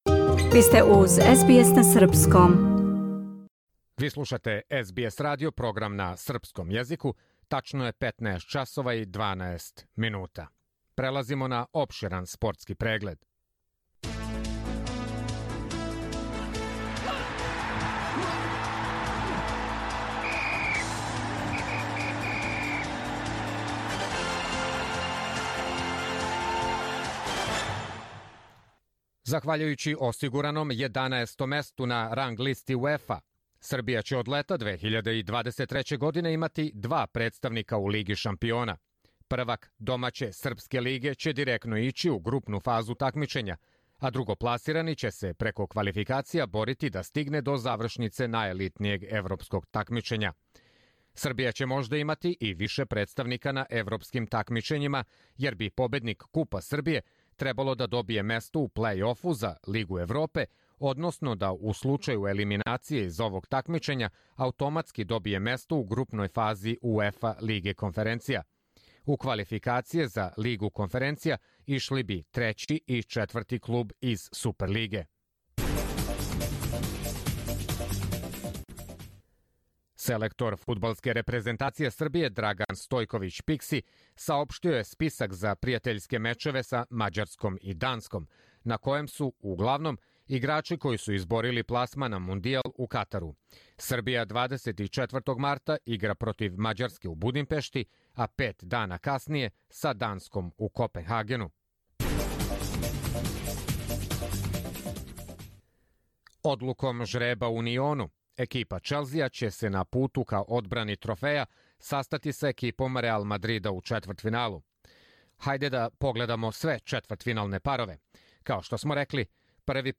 Ђоковић је потврдио да сезону на шљаци почиње на мастерсу у Монте Карлу, а потврдио је и да ће играти на "Србија опену". Слушајте опширан спортски извештај.